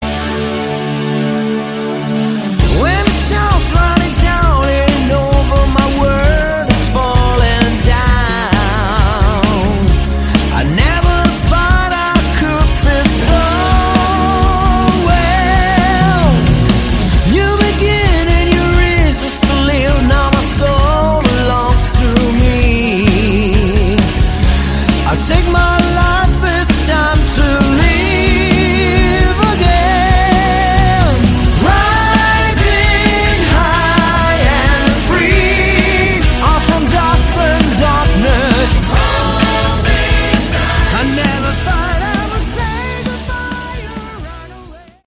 Category: Hard Rock
drums
guitar
keyboards
bass
vocal